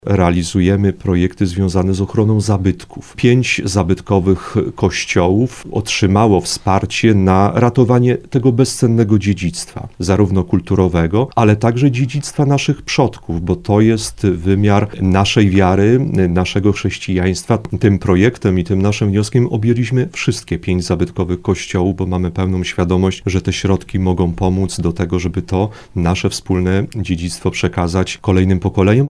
Jak podkreślał w rozmowie Słowo za Słowo wójt Grzegorz Gotfryd, zadanie ma na celu ochronę dziedzictwa chrześcijańskiego i kulturowego.